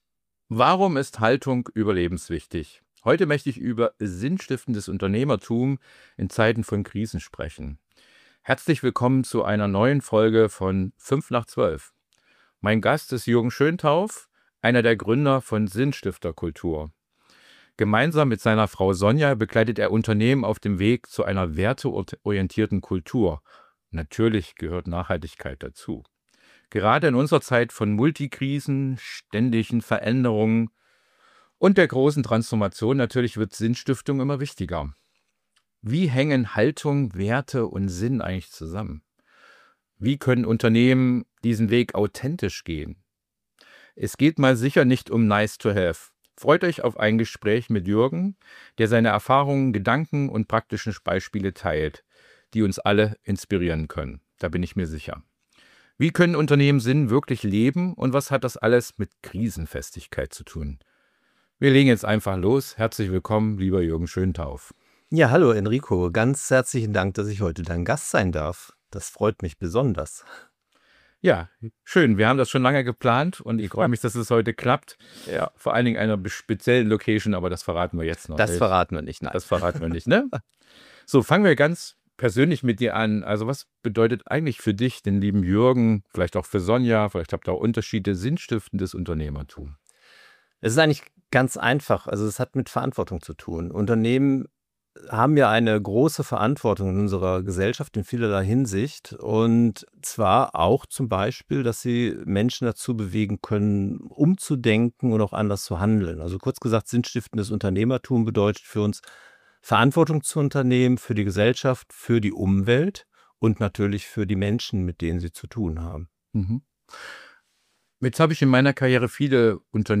Das Gespräch verbindet persönliche Erfahrungen mit praktischen Beispielen aus der Unternehmenswelt und macht deutlich, wie wichtig Orientierung gerade in Zeiten von Unsicherheit und Transformation ist.